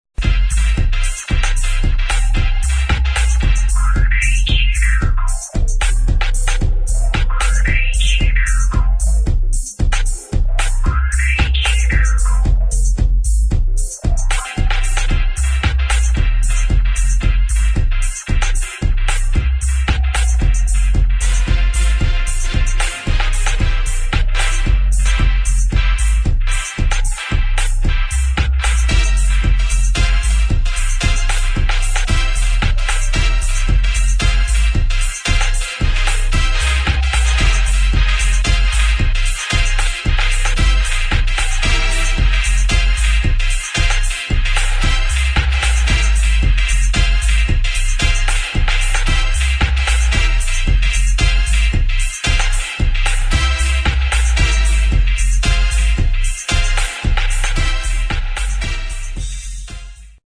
[ DUB | REGGAE ]